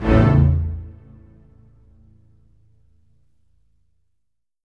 ORCHHIT A1-R.wav